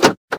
vehiclelock.ogg